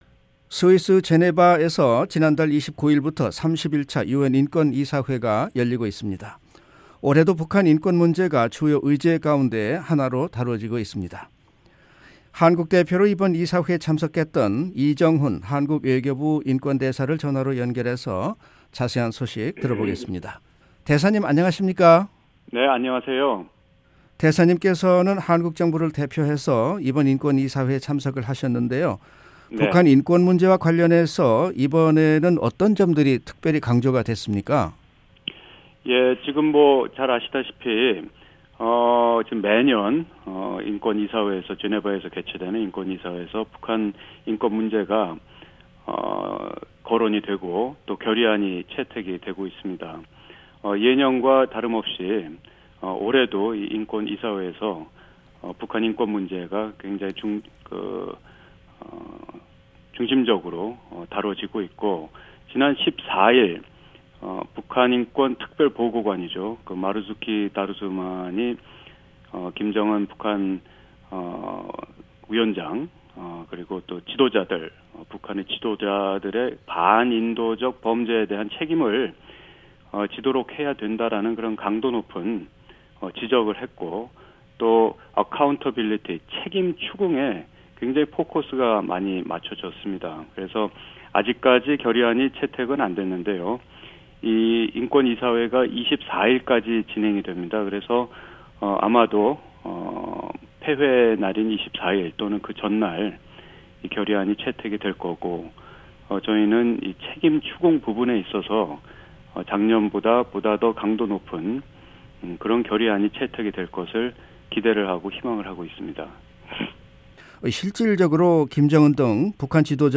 [인터뷰] 한국 외교부 이정훈 인권대사